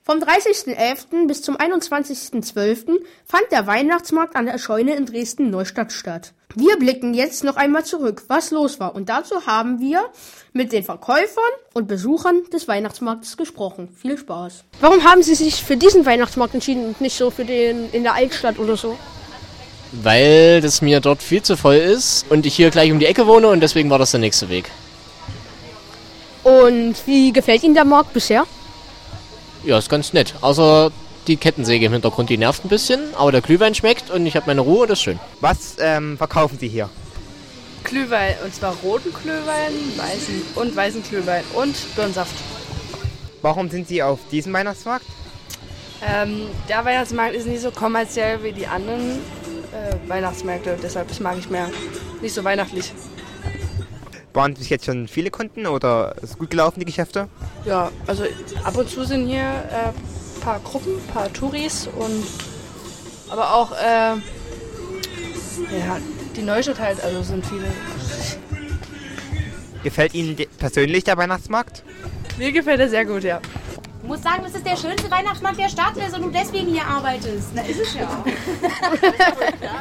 Jetzt ist die Weihnachtssendung fertig und zum letzten Mal dieses Jahr gibts von unserer Kinderradioredaktion “Listen2Radio” was auf die Ohren.
Unsere “Mini”redakteure haben sich über das Thema Weihnachten schlau gemacht, waren auf dem Neustädter Weihnachtsmarkt unterwegs und hatten einen Heidenspaß in der Weihnachtsküche beim Punschen und Weihnachtslieder trällern.
weihnachtsmarkt_schnitt.mp3